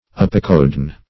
Search Result for " apocodeine" : The Collaborative International Dictionary of English v.0.48: Apocodeine \Ap`o*co*de"ine\, n. [Pref. apo- + codeine.]